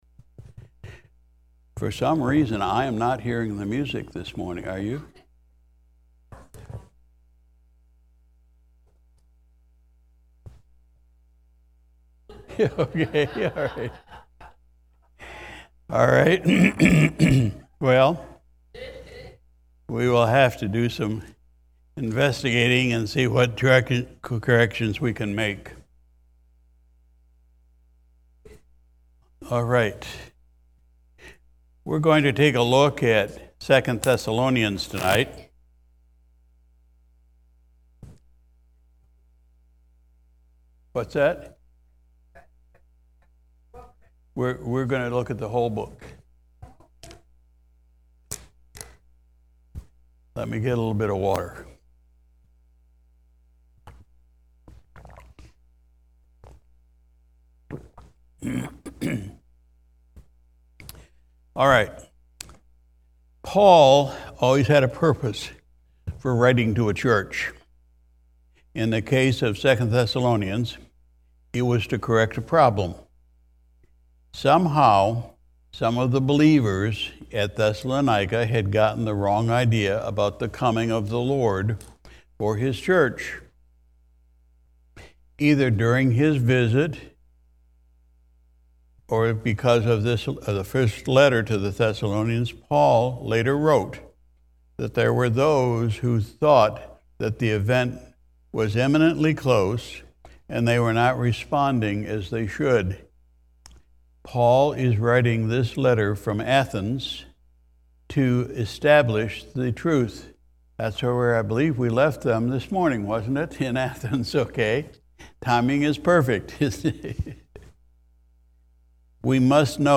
May 28, 2023 Sunday Evening Service We continued our study in the Book of 2 Thessalonians